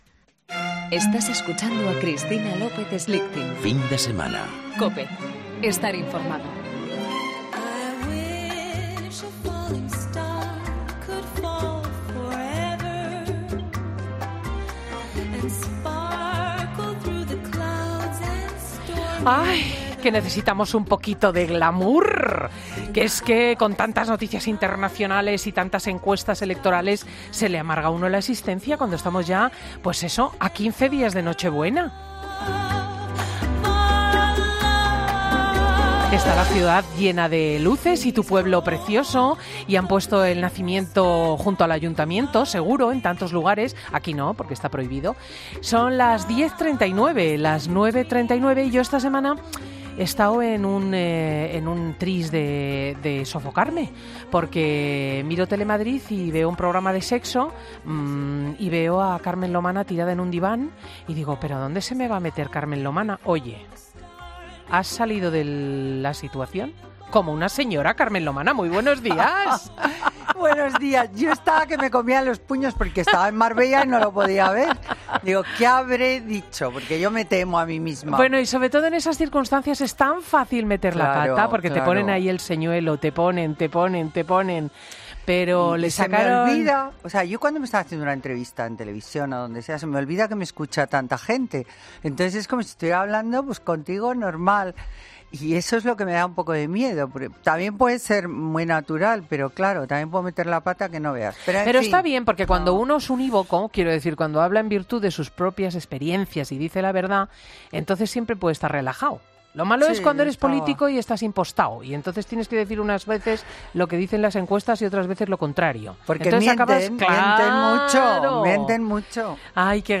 AUDIO: Escucha a Carmen Lomana atendiendo a los oyentes y las preguntas que le mandan.